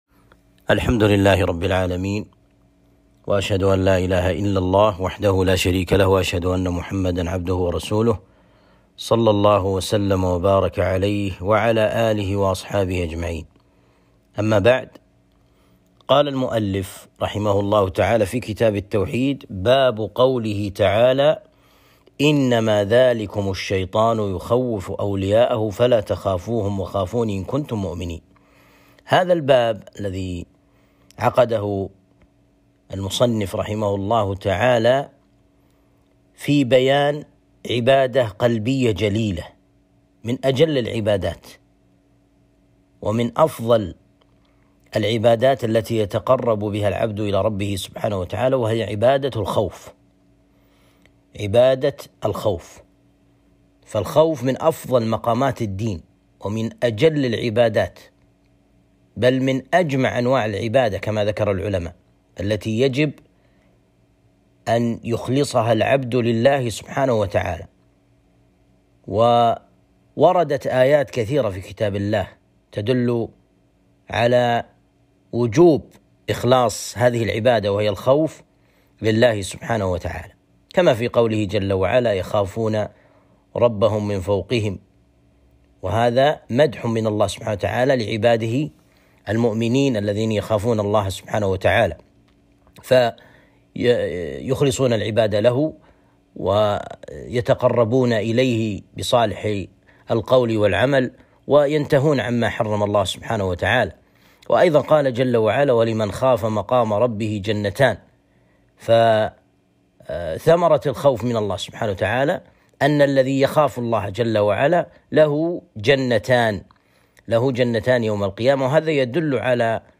الدروس